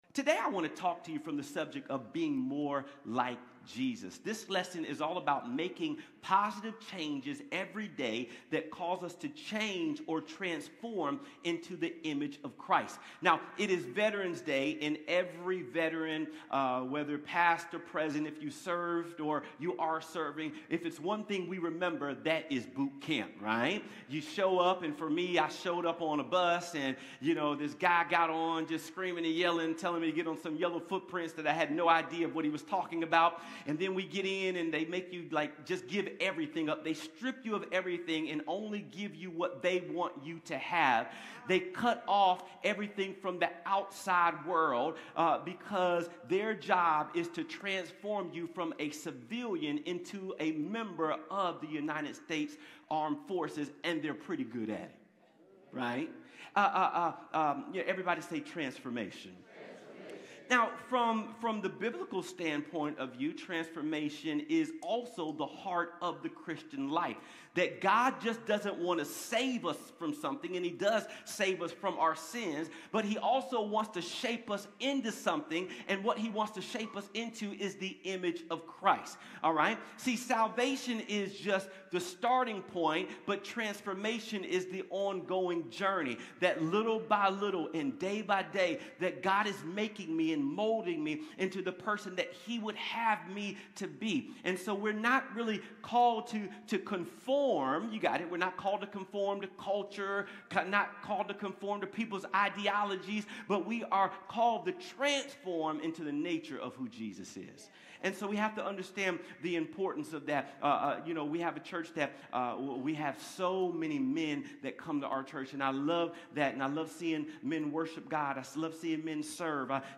Connect Groups Events Watch Church Online Sermons Give Becoming More Like Jesus November 9, 2025